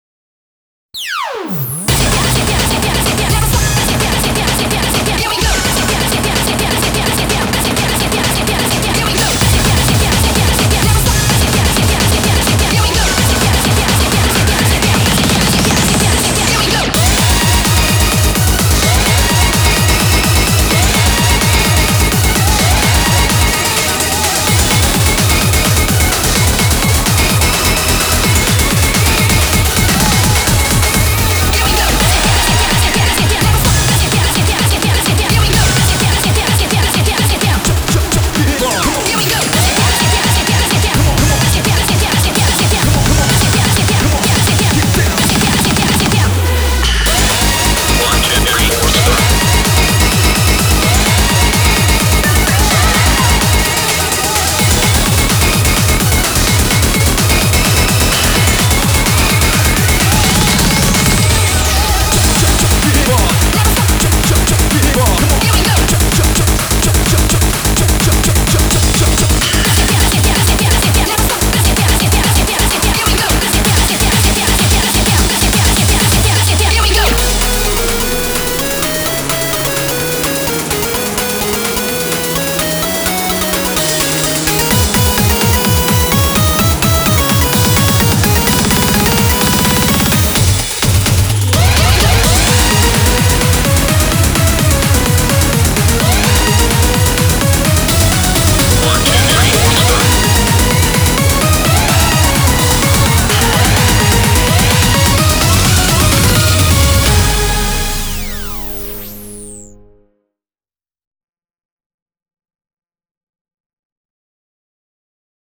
BPM255
Audio QualityPerfect (High Quality)
Comentarios[HYPER SPEED RAVE]